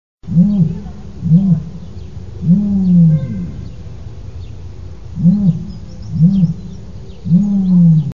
صوت النعامة
يسمى صوت النعامة بالزمار .
Ostrich-Booming-Sound-1.mp3